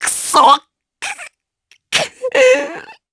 Neraxis-Vox_Sad_jp.wav